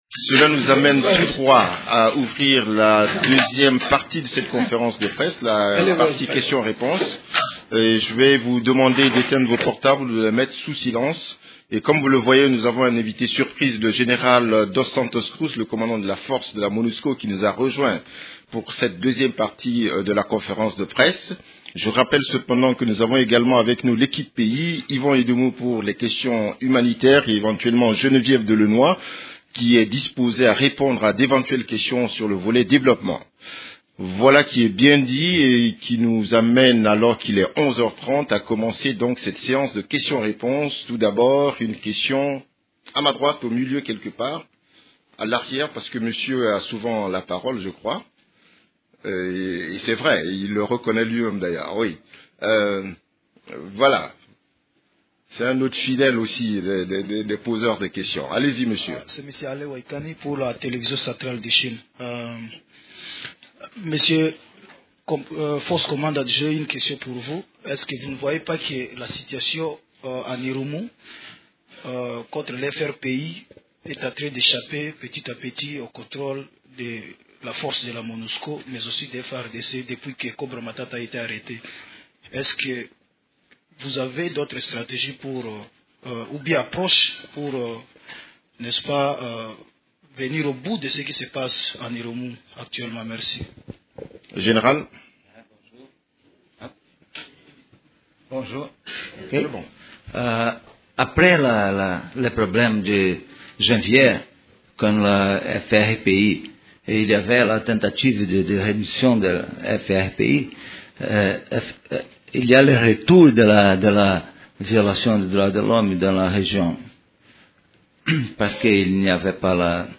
Conférence de presse du 8 avril 2015
La conférence hebdomadaire des Nations unies du mercredi 8 avril à Kinshasa a essentiellement tourné autour des activités des composantes de la Monusco, celles de l’Equipe-pays ainsi de la situation militaire.